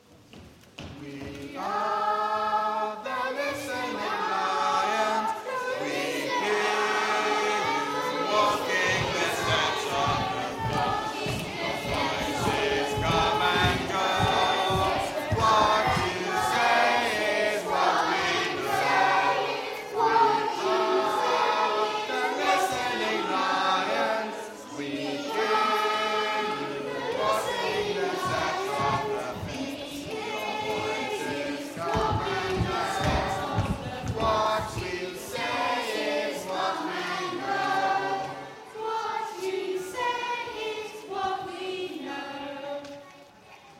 Singing History Concert 2016: Fitz Lions 2
The introductory song is a round based on the main concept of the poem